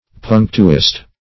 punctuist - definition of punctuist - synonyms, pronunciation, spelling from Free Dictionary
Punctuist \Punc"tu*ist\, n.